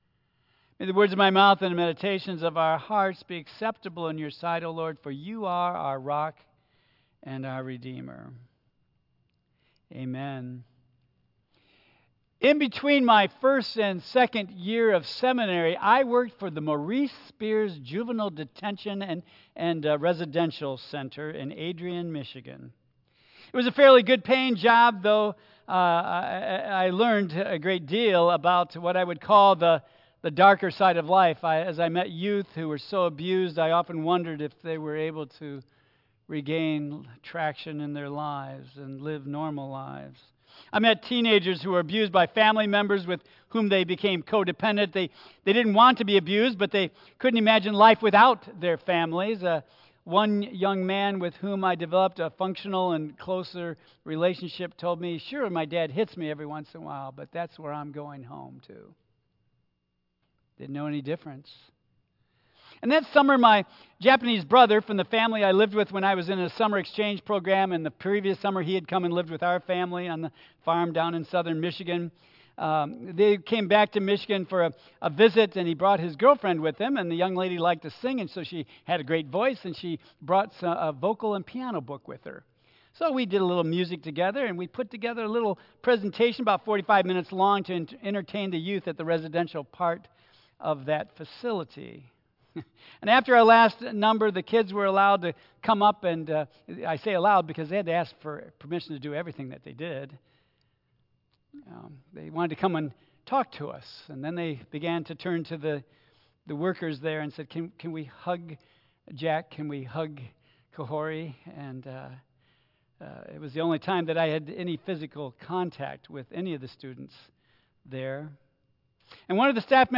Tagged with Michigan , Sermon , Waterford Central United Methodist Church , Worship Audio (MP3) 9 MB Previous The Attitude of Gratitude Next The Bread of Life